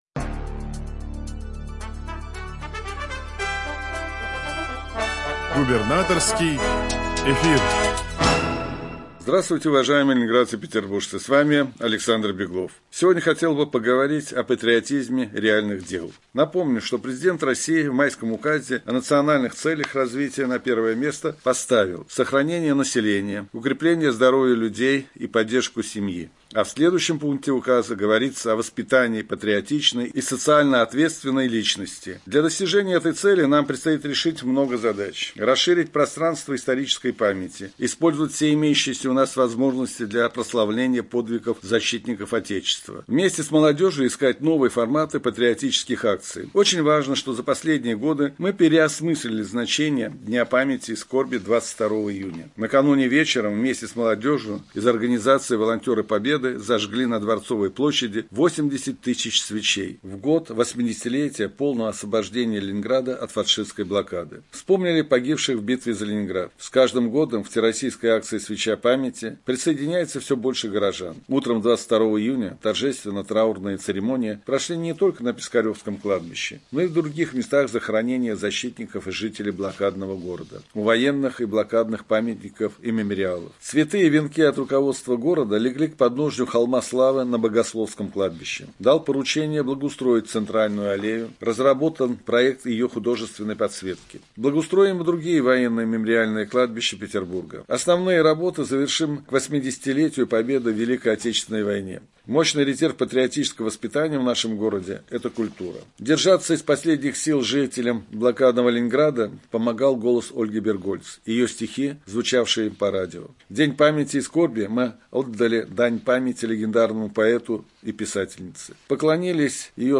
Радиообращение – 24 июня 2024 года
Радиообращение_24-06.mp3